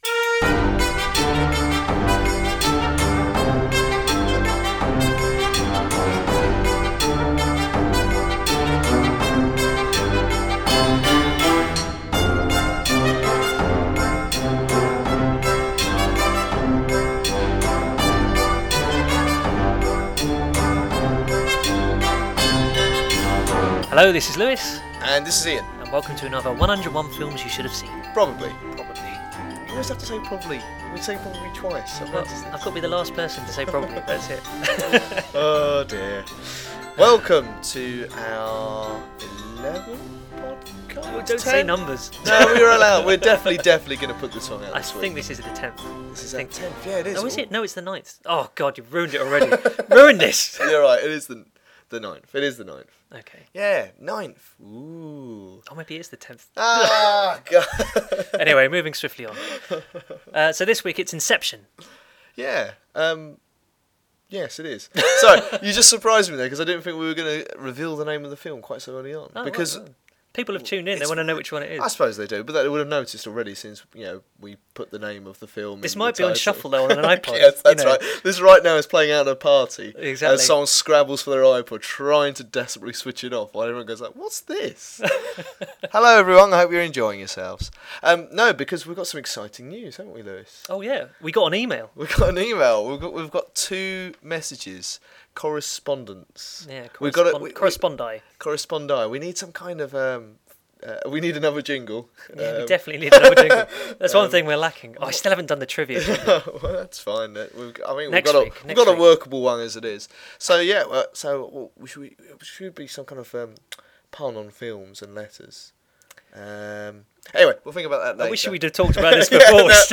and spend much of the podcast mispronouncing the name of pretty much every single actor in Inception.